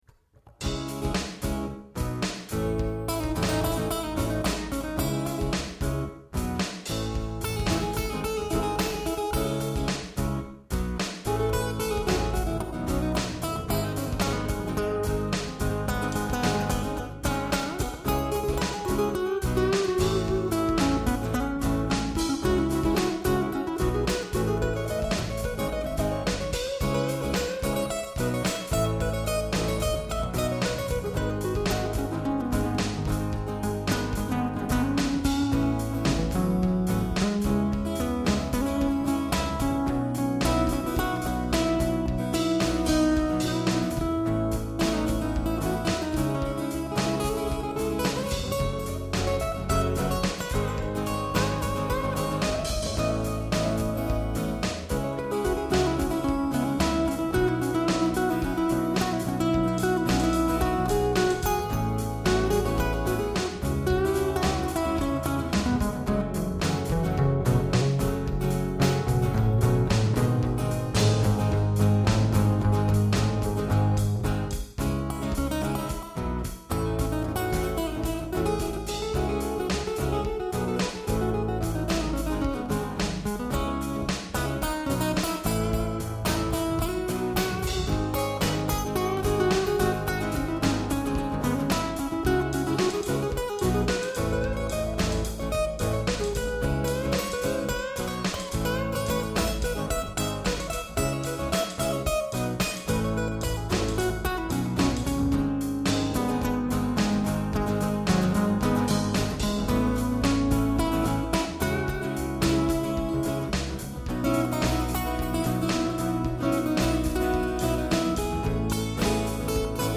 World music
Jazz